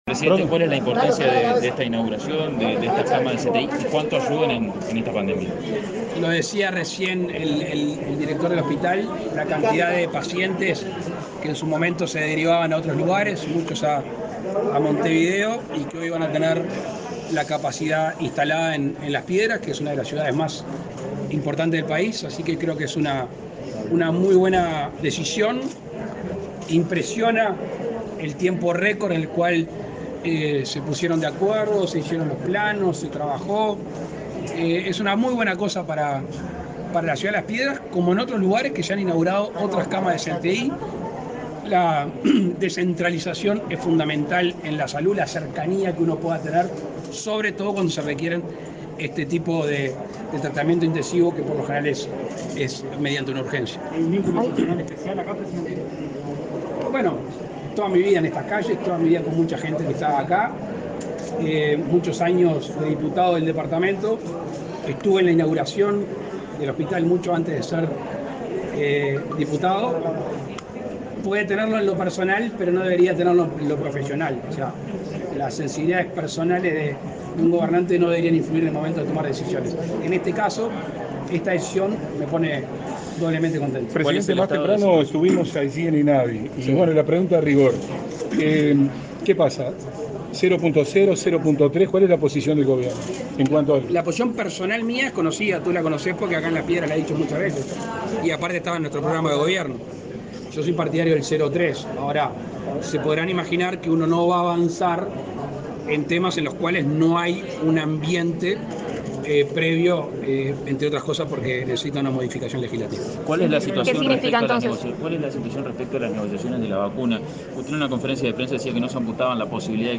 Declaraciones a la prensa del presidente Luis Lacalle Pou
Al finalizar su visita a la ciudad de Las Piedras, el mandatario brindó declaraciones a los representantes de los medios presentes.